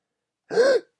男性喘息 1
描述：男性喘着粗气
标签： 一声惊呼 呼吸 声音
声道立体声